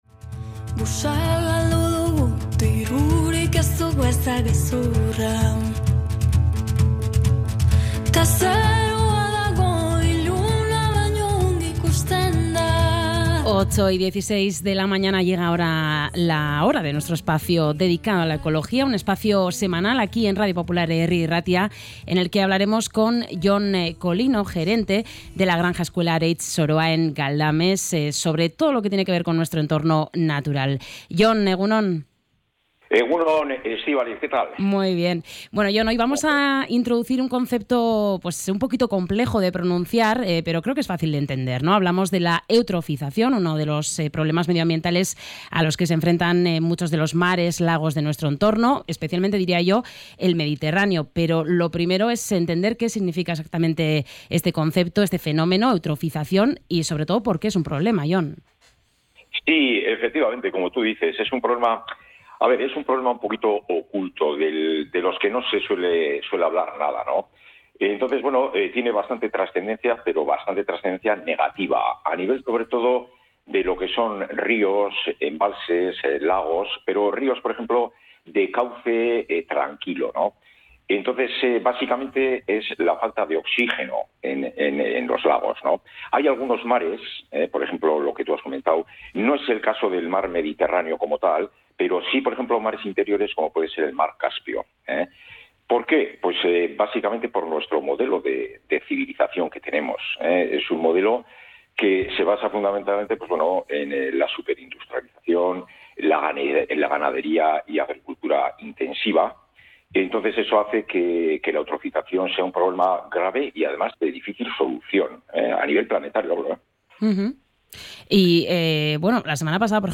A lo largo de la conversación, el invitado ha definido el fenómeno y ha repasado sus causas y efectos más visibles.